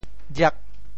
蒻 部首拼音 部首 艹 总笔划 13 部外笔划 10 普通话 ruò 潮州发音 潮州 riag8 文 中文解释 箬 <名> 竹笋壳 [skin of bamboo shoots] 箬,楚谓竹皮曰箬。